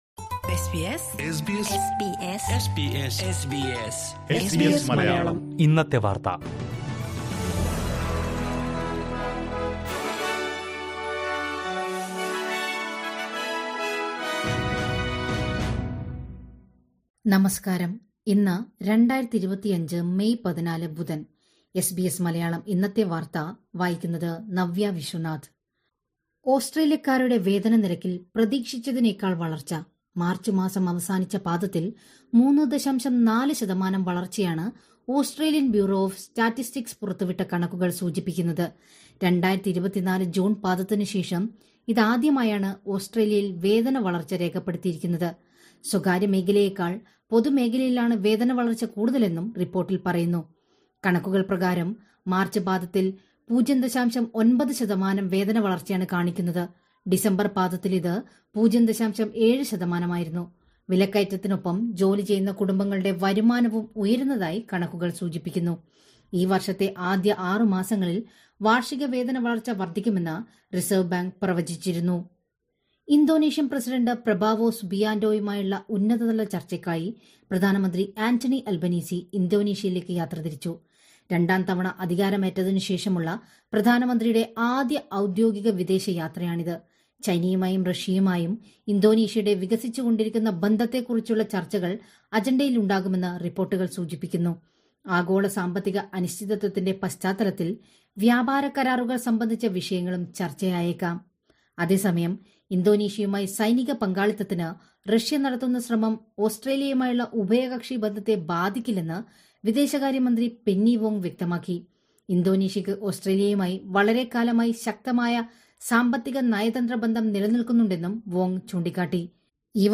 2025 മേയ് 14ലെ ഓസ്‌ട്രേലിയയിലെ ഏറ്റവും പ്രധാന വാര്‍ത്തകള്‍ കേള്‍ക്കാം...